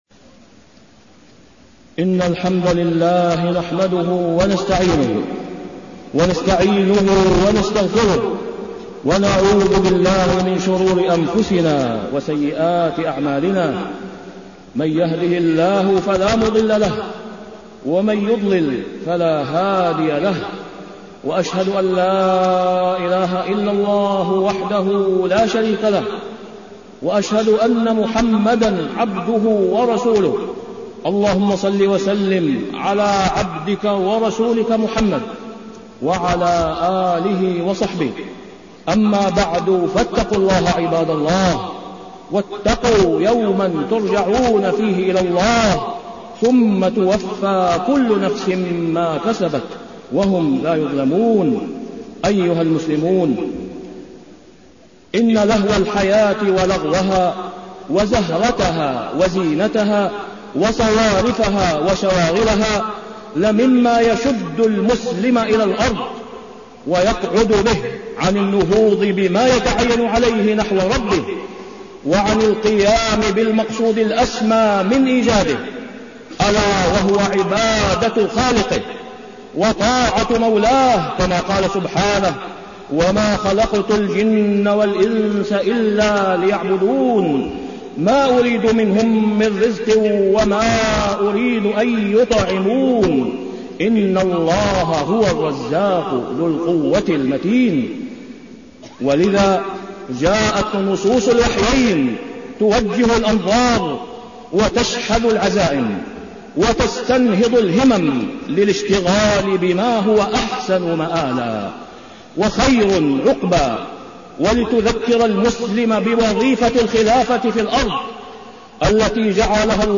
تاريخ النشر ٩ محرم ١٤٢٩ هـ المكان: المسجد الحرام الشيخ: فضيلة الشيخ د. أسامة بن عبدالله خياط فضيلة الشيخ د. أسامة بن عبدالله خياط غاية خلق العباد عبادة رب العباد